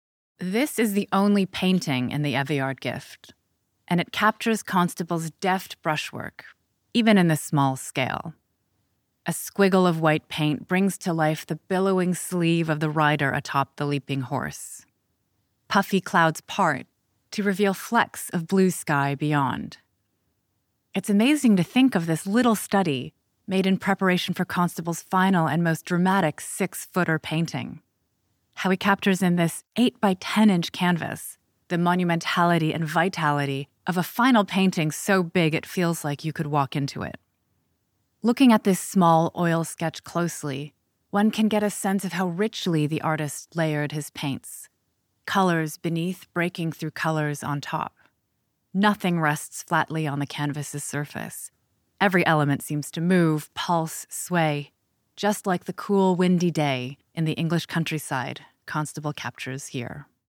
268 — (1) Spoken Label and (2) Curator's Personal Reflection
Curator's Personal Reflection